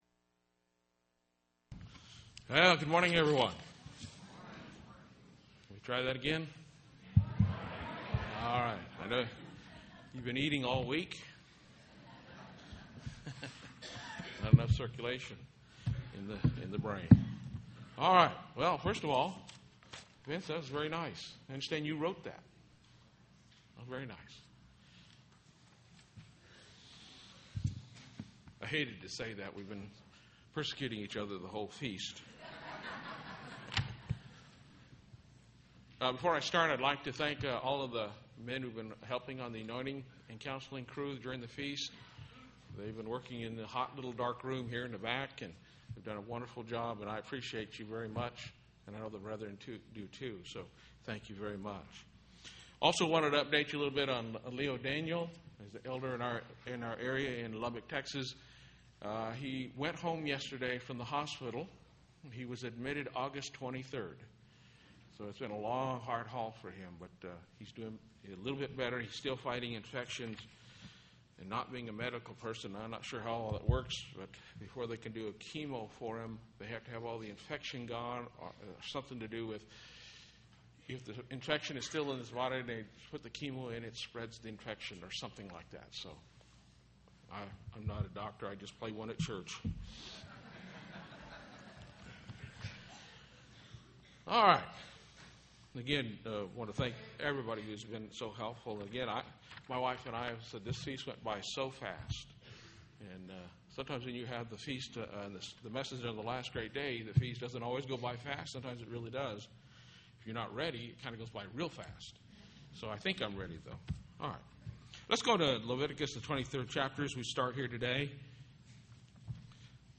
This sermon was given at the Kerrville, Texas 2010 Feast site.